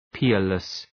Προφορά
{‘pıərlıs}